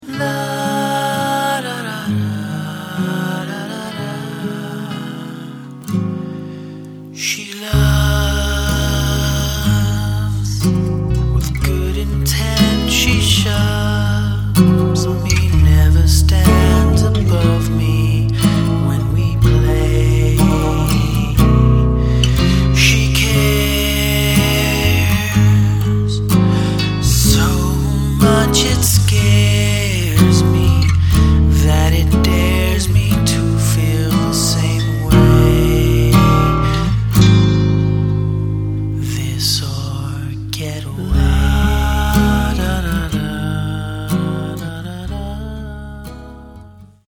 Indie Folk